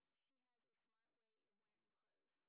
sp26_street_snr20.wav